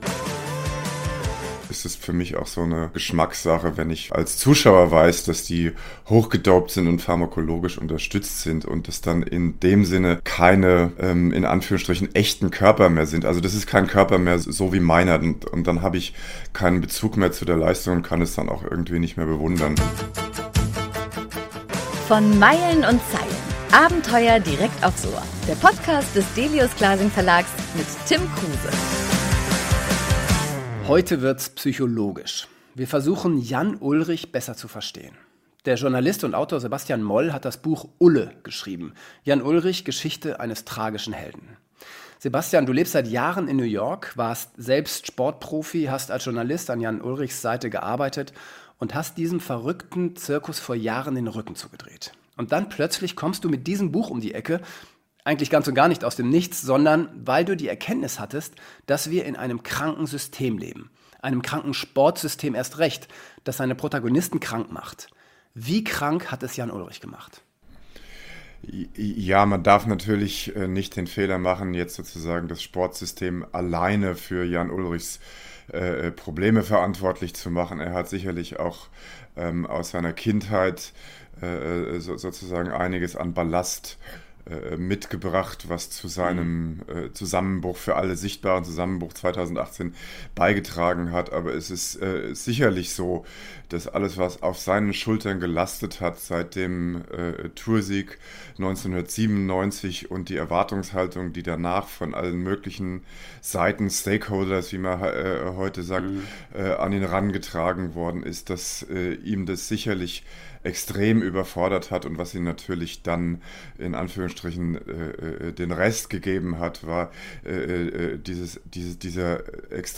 im heutigen Gespräch